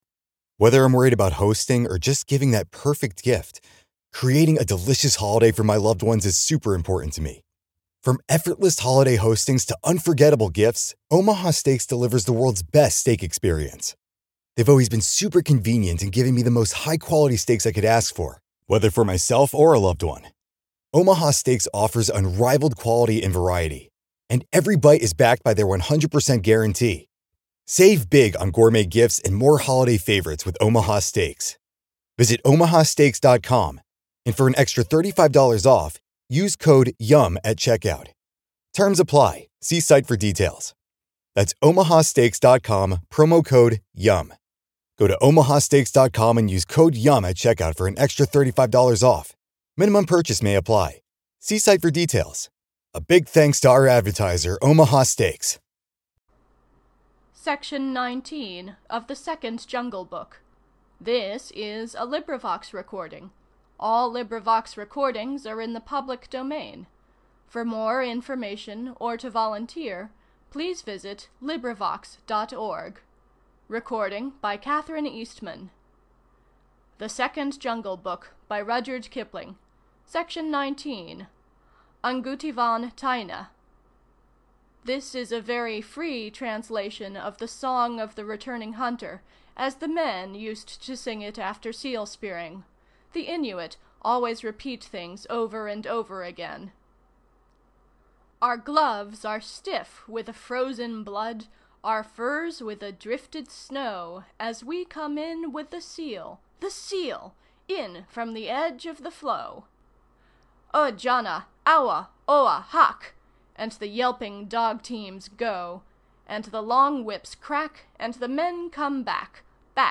This is a collaborative reading.